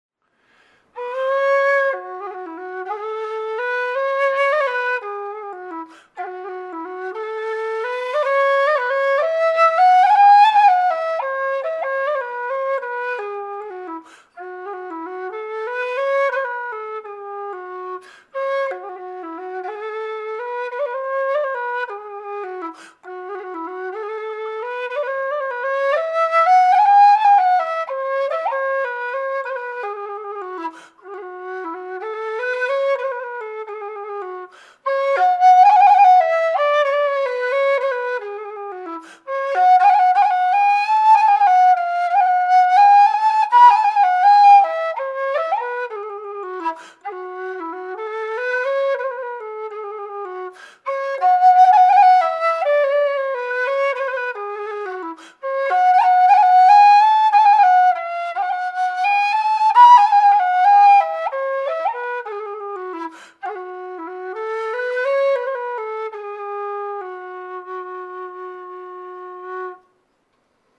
Attach:lowE_whistle.jpg Δ | low E whistle
made out of thin-walled aluminium tubing with 20mm bore